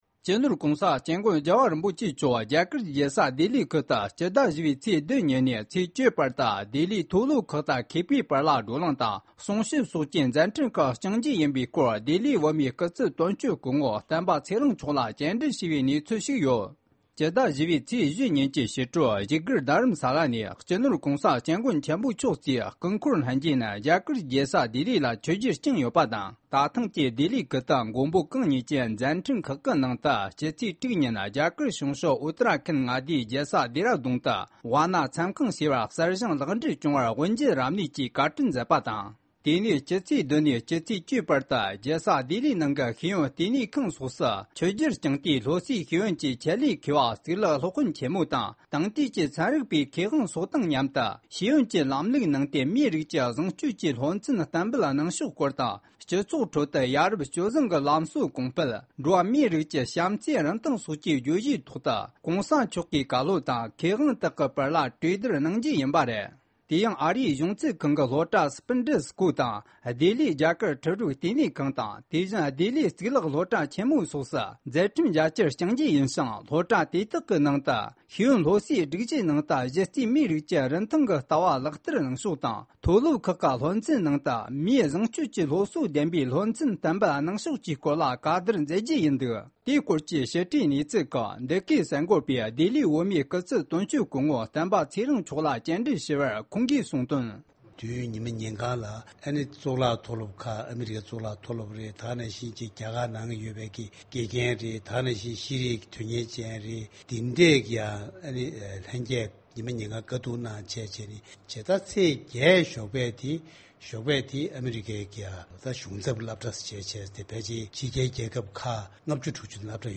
བཅར་འདྲི་ཞུས་ཡོད་པ་རེད།